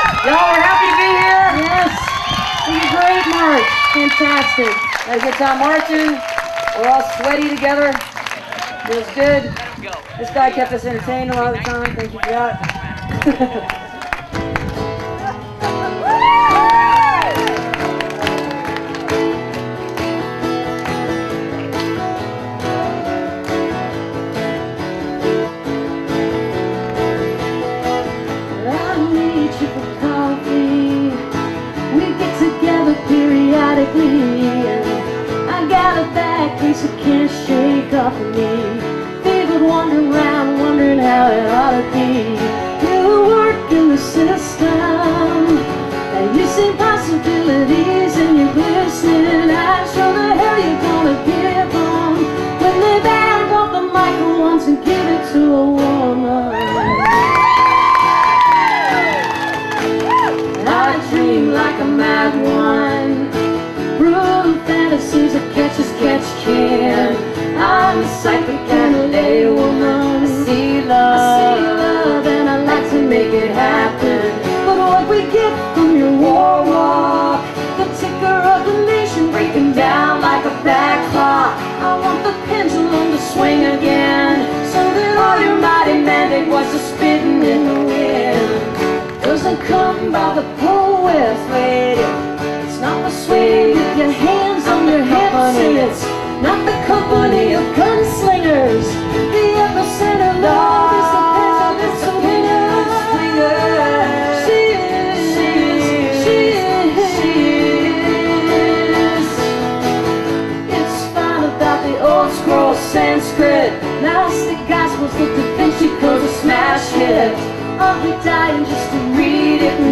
(audio capture from youtube)
(acoustic duo show)